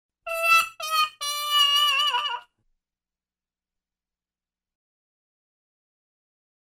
Laughing Trumpet